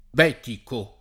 vai all'elenco alfabetico delle voci ingrandisci il carattere 100% rimpicciolisci il carattere stampa invia tramite posta elettronica codividi su Facebook betico [ b $ tiko ] etn.; pl. m. ‑ci — sim. il top. f. stor.